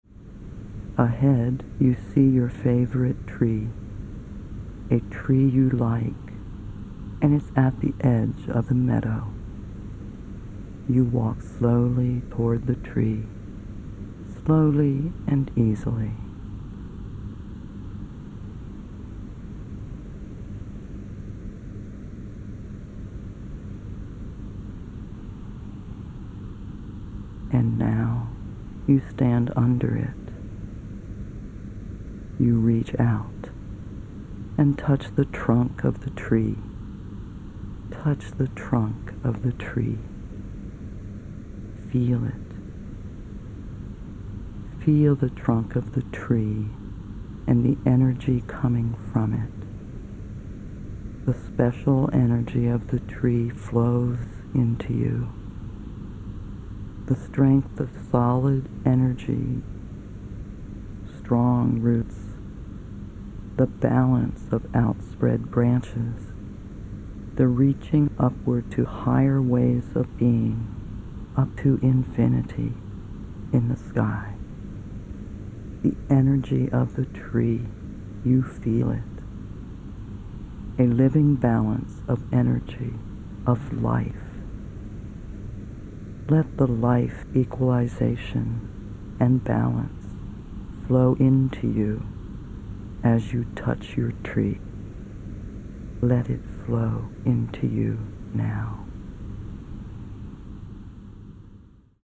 Dosáhněte zvýšení vitality a osobní síly pomocí verbálně vedené cesty do Vašeho nitra k přírodním zdrojům energie.…